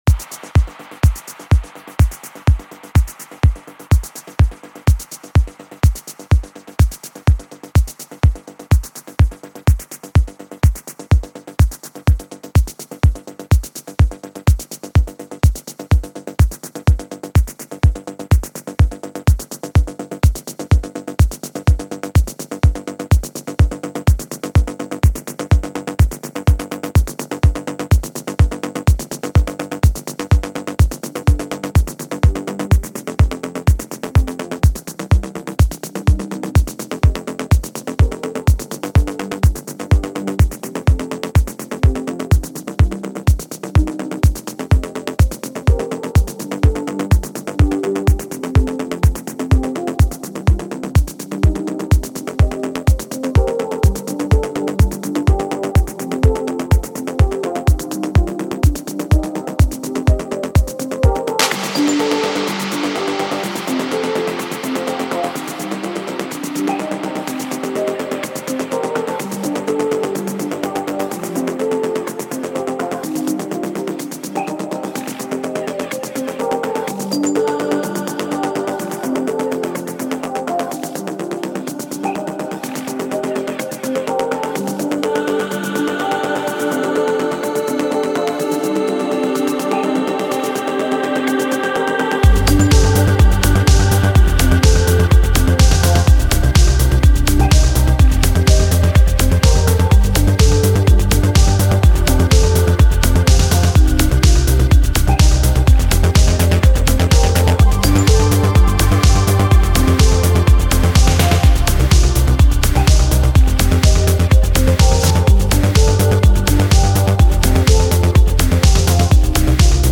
Жанр: Красивая музыка